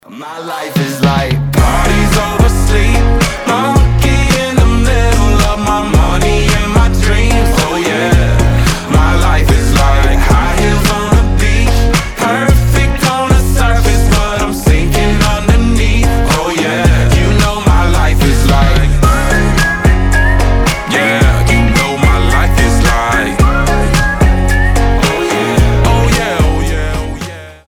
• Качество: 320, Stereo
позитивные
свист
кантри